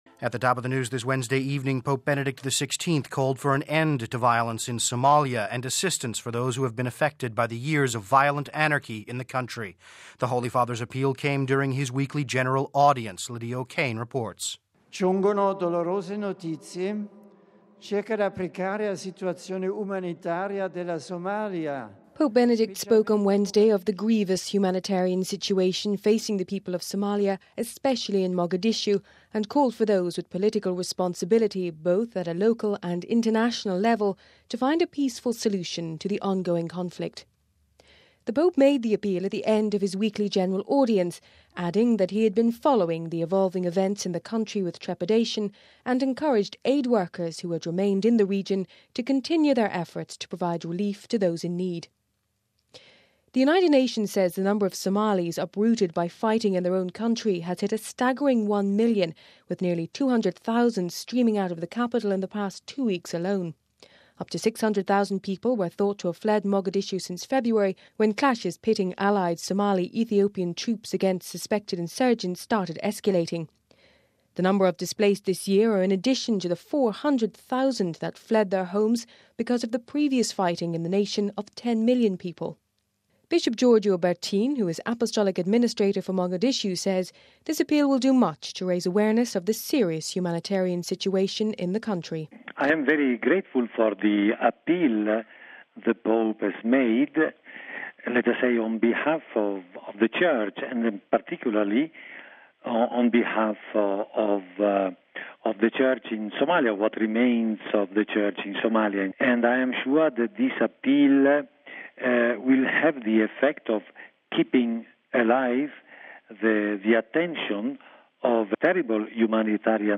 He made his remarks during his weekly general audience...